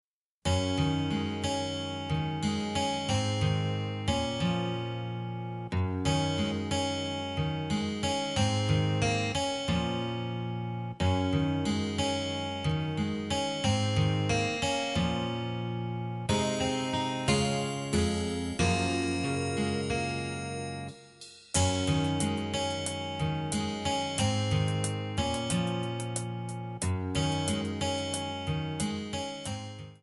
Bb/C
MPEG 1 Layer 3 (Stereo)
Backing track Karaoke
Pop, 1990s